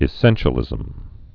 (ĭ-sĕnshə-lĭzəm)